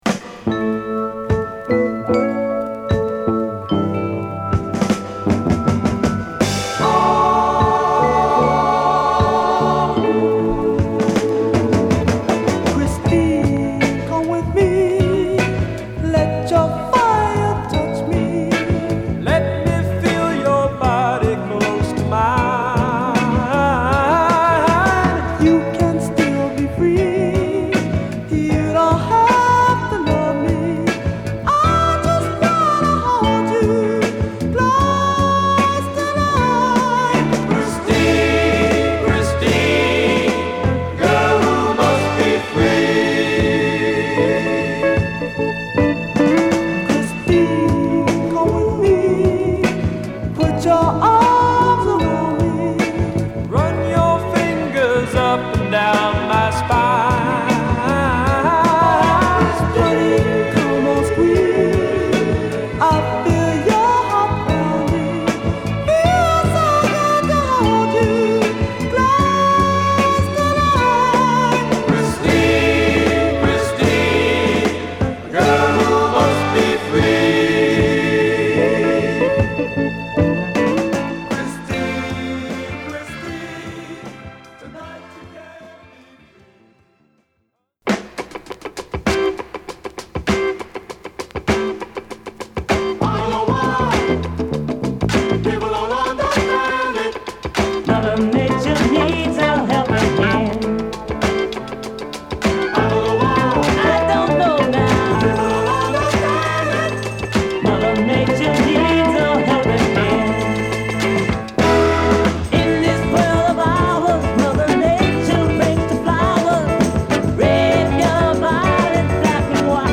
ファルセットのリードで瑞々しいコーラスを加えたミディアム・フィリー・ソウル